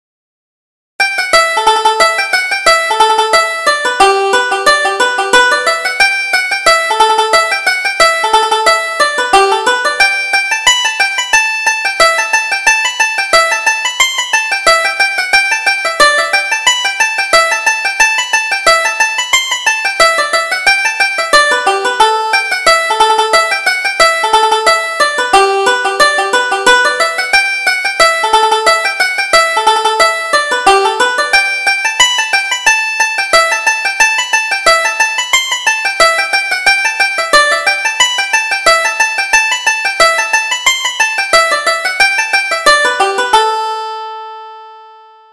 Reel: The Pigeon on the Gate